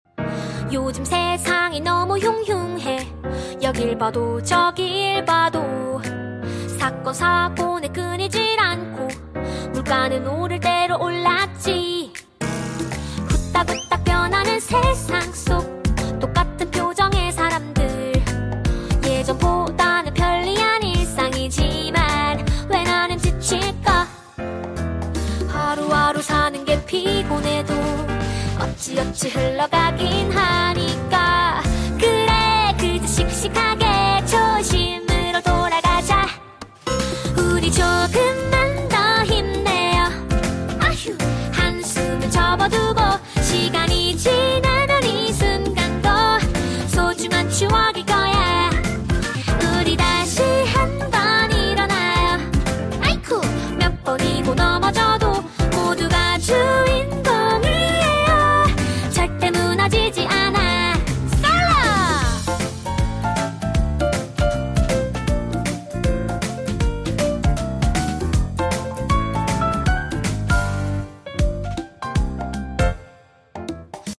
귀엽고 발랄한 CM송